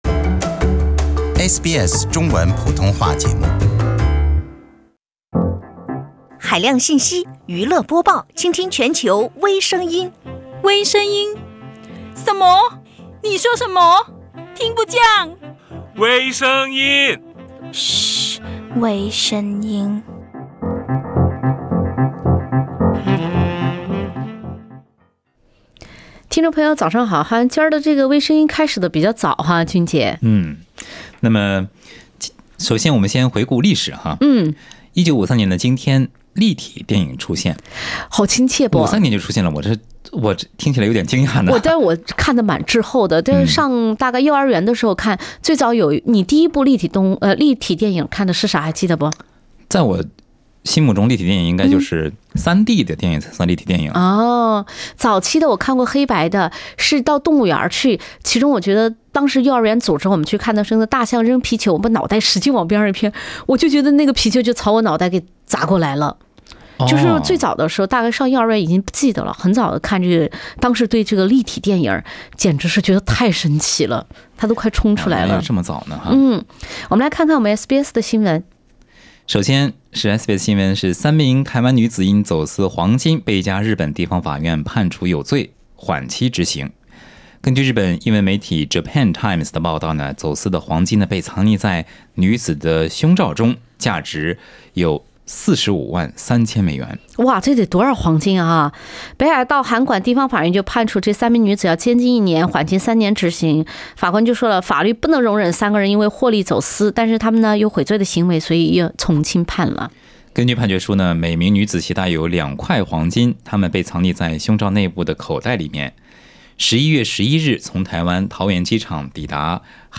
女子用胸衣走私黄金遭没收，男子中彩票后用大粪向老板复仇，男子为大龄女友办假证被判刑，微博主直播“炸街”竟是虚构。另类轻松的播报方式，深入浅出的辛辣点评，包罗万象的最新资讯，倾听全球微声音。